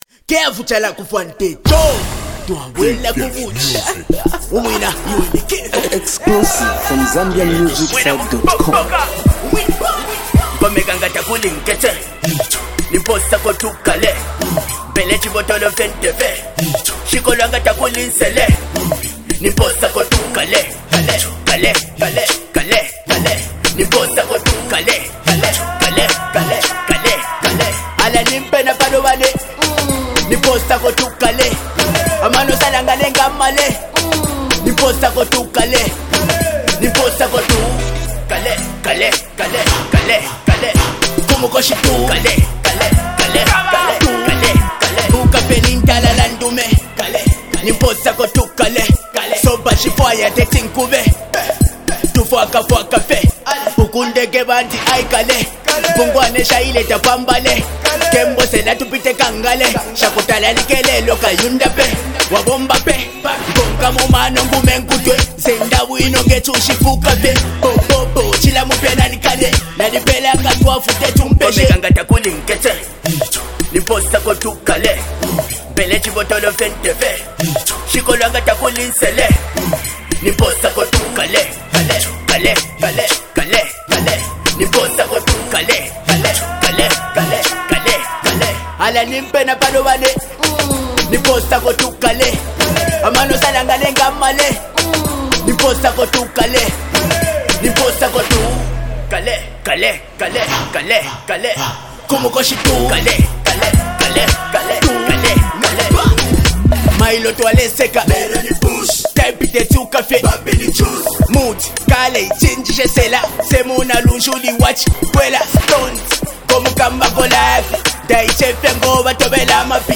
mad rhumba trap fusion